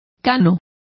Complete with pronunciation of the translation of grayer.